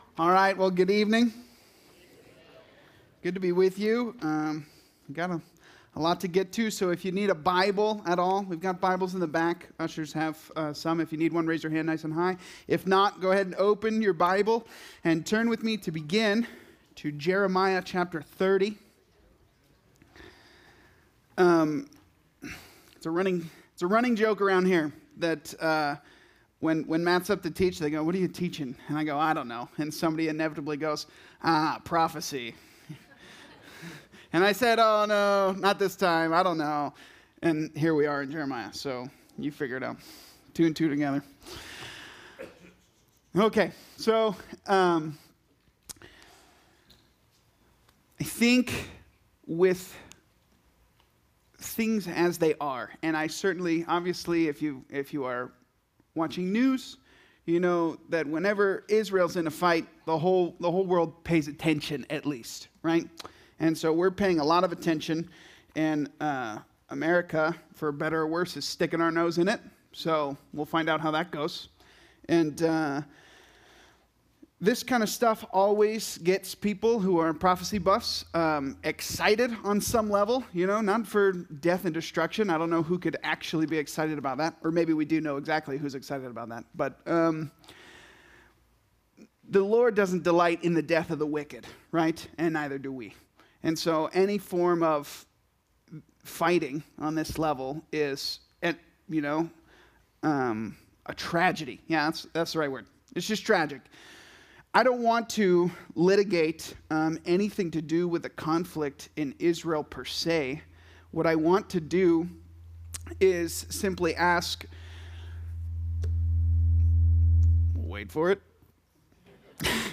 Calvary Chapel Saint George - Sermon Archive
Related Services: Wednesday Nights